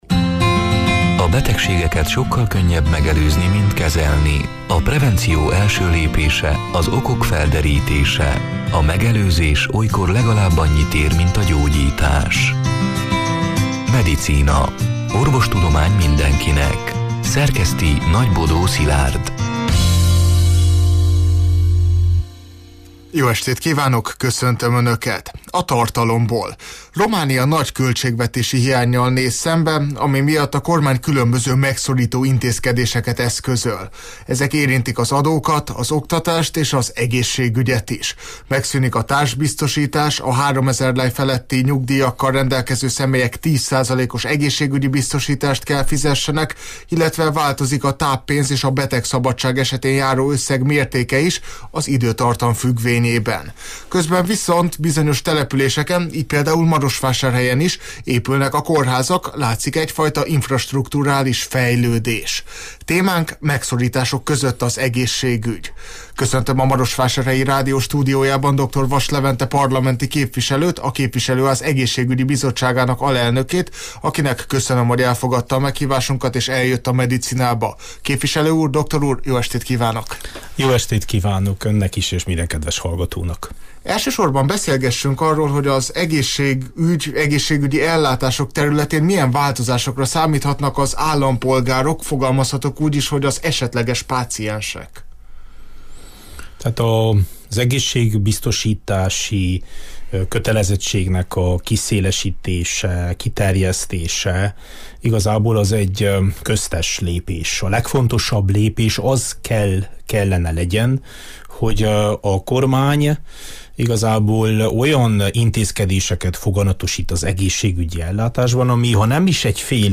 A Marosvásárhelyi Rádió Medicina (elhangzott: 2025. augusztus 13-án, szerdán este nyolc órától élőben) c. műsorának hanganyaga: